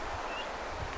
pajulinnun yksi ääni
vit-tiltalttien kanssa pienen lammen rannalla
pajulinnun_yksi_aani.mp3